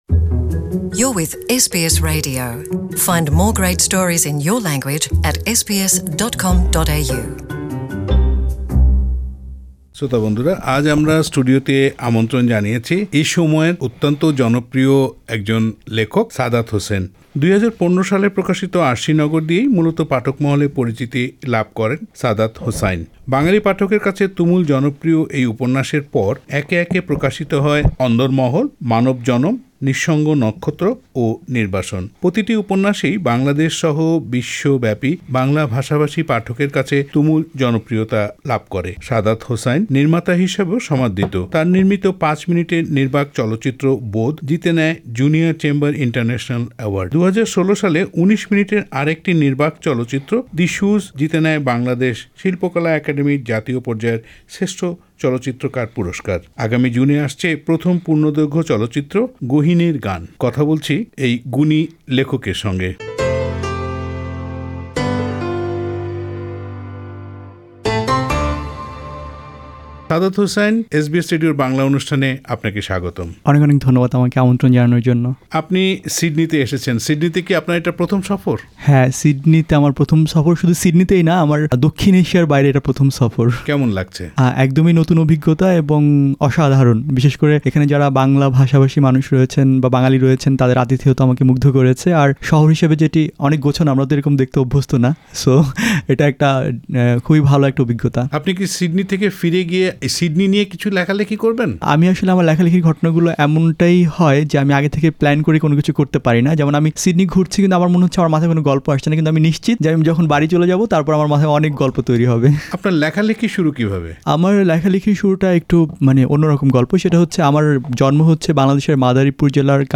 বাংলাদেশের জনপ্রিয় লেখক ও চলচ্চিত্র নির্মাতা সাদাত হোসাইন কথা বলেছেন এসবিএস বাংলার সঙ্গে।
লেখক সাদাত হোসাইনের সাক্ষাৎকারটি বাংলায় শুনতে উপরের অডিও প্লেয়ারটিতে ক্লিক করুন।